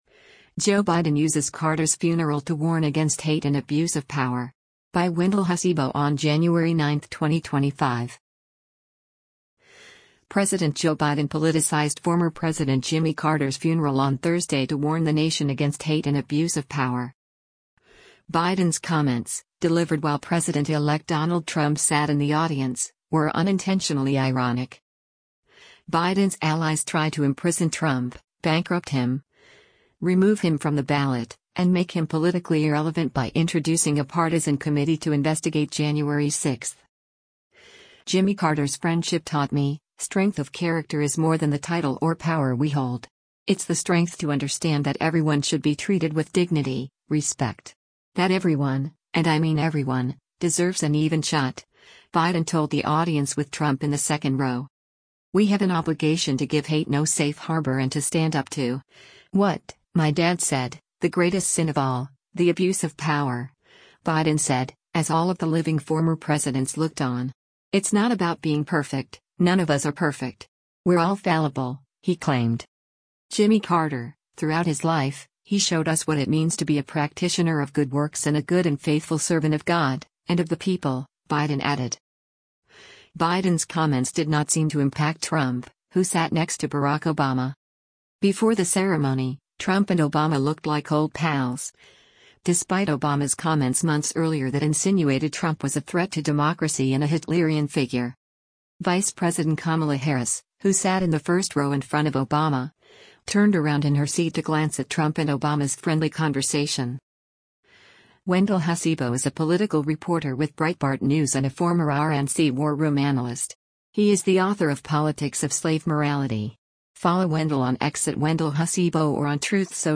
U.S. President Joe Biden delivers a eulogy during the state funeral for former U.S. Presid